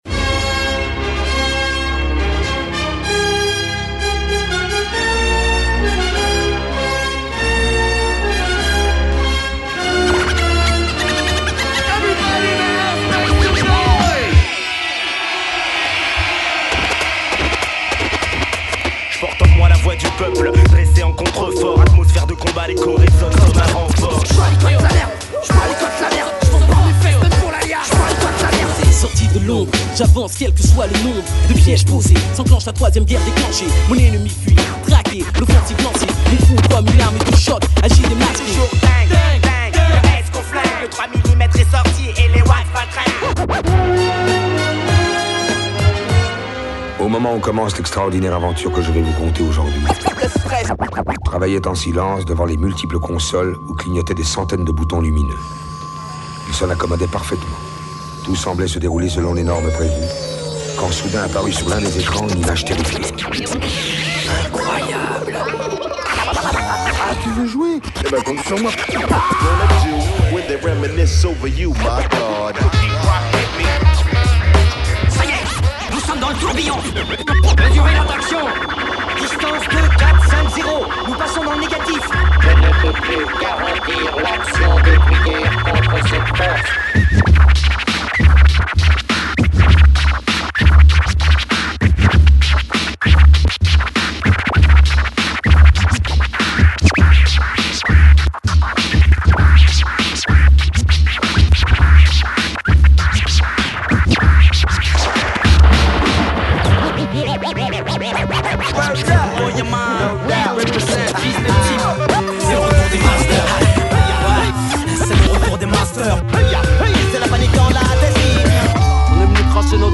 Genre : Blues